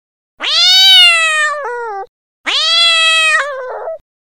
zvuk-orushhego-kota_006
zvuk-orushhego-kota_006.mp3